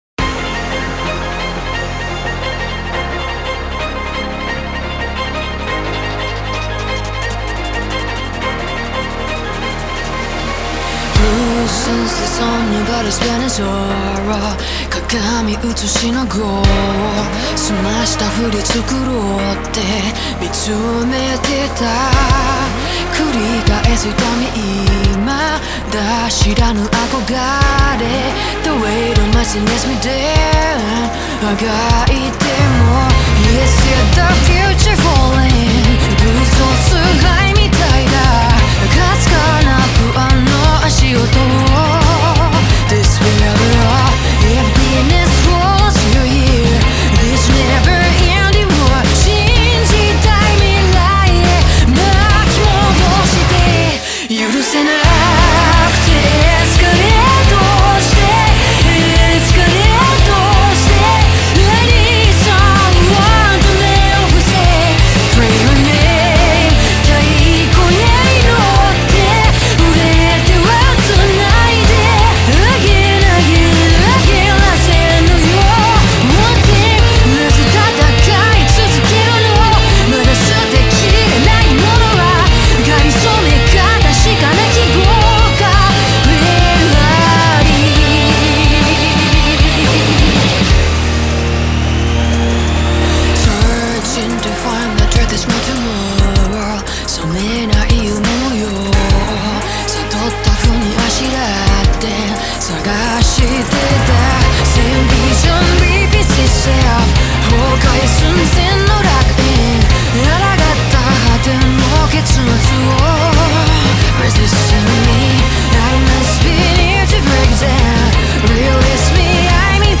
Genre : Anime.